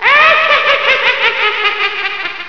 Witch"s Laugh